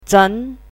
chinese-voice - 汉字语音库
zhen2.mp3